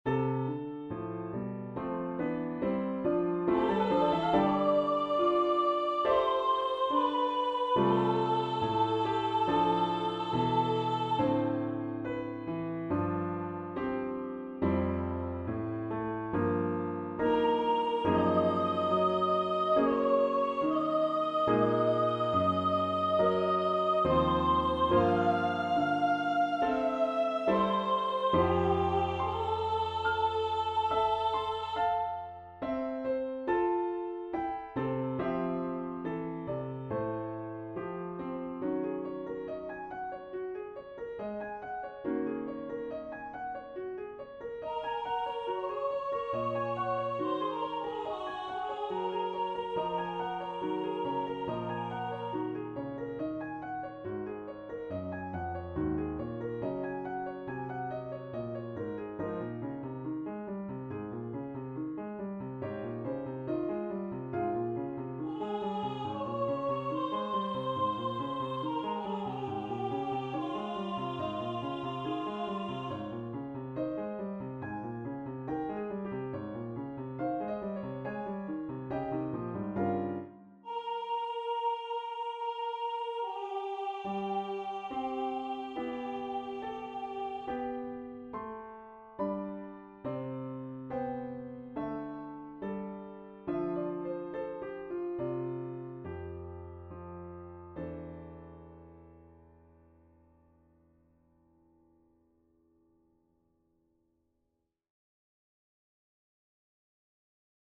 1v Voicing: Unison Genre: Secular, Art song
Language: English Instruments: Keyboard
First published: 2025 Description: One of a series of songs about different colors for voice and piano.